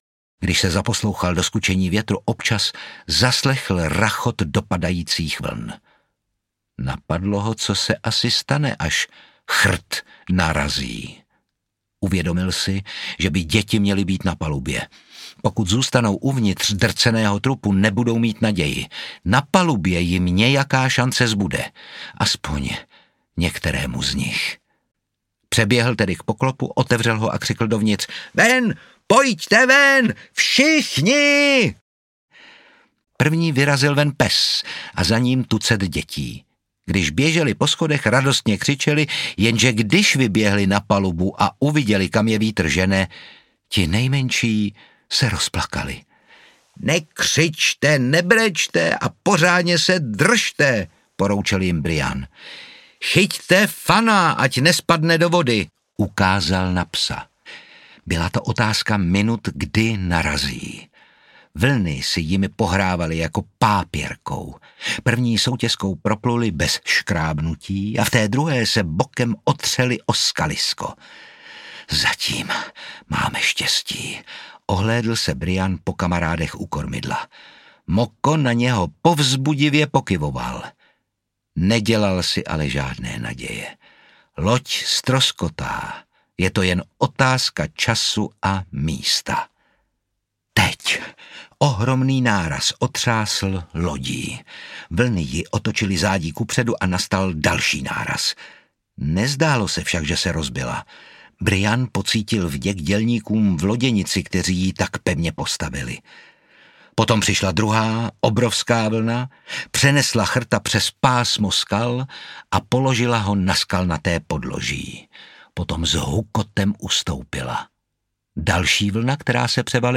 Dva roky prázdnin audiokniha
Ukázka z knihy
• InterpretOtakar Brousek ml.